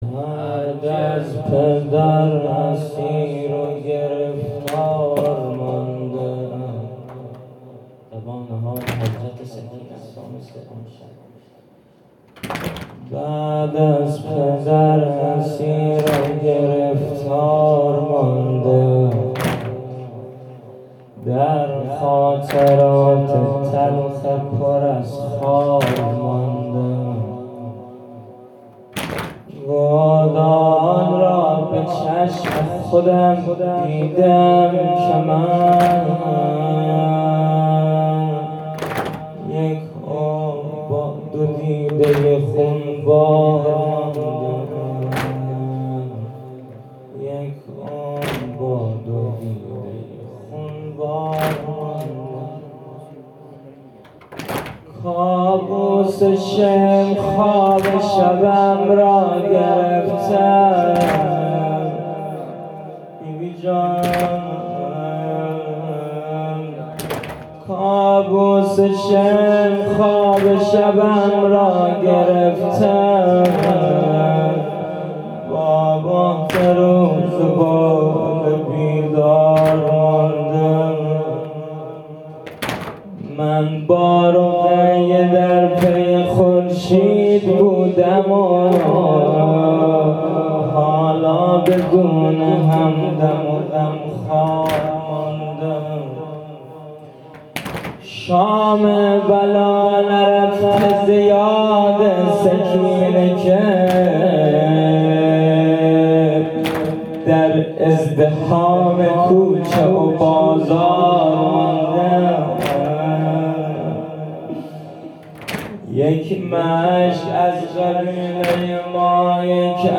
هیئت صادقیون زابل
شام شهادت حضرت سکینه(س)/20مهر1400 /5ربیع الاول1443